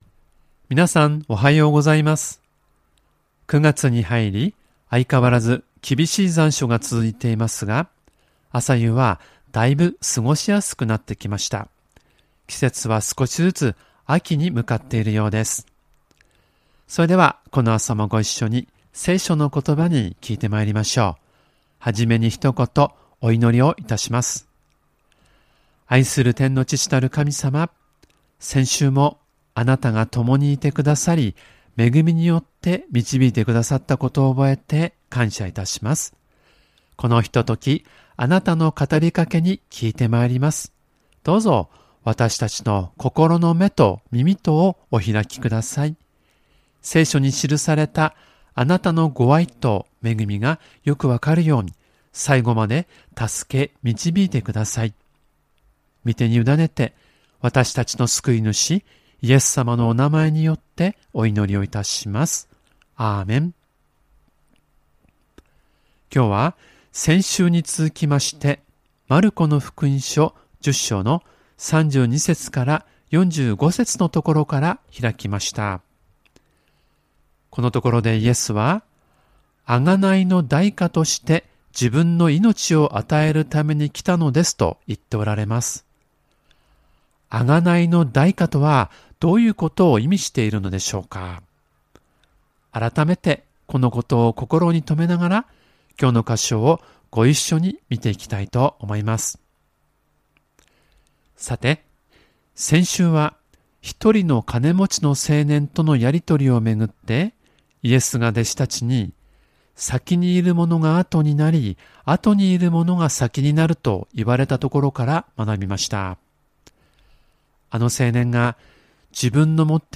●主日礼拝メッセージ（赤文字をクリックするとメッセージが聴けます。